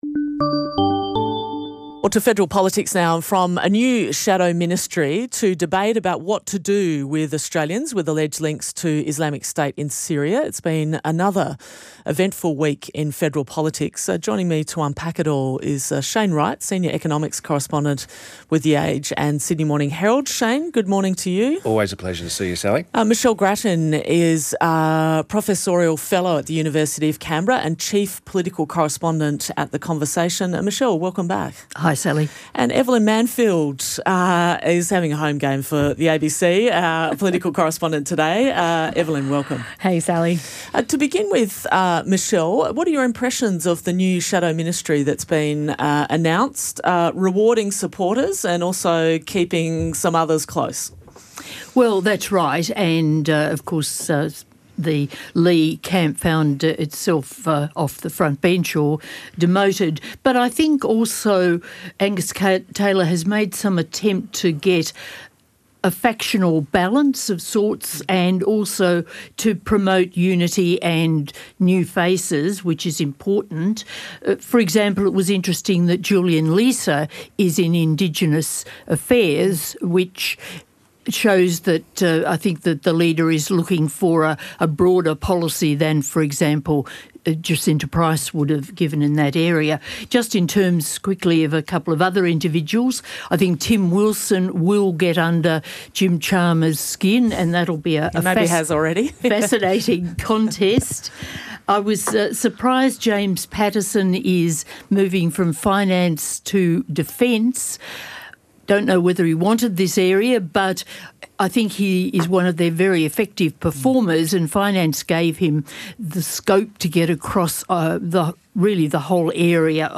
Our Friday political panel discuss.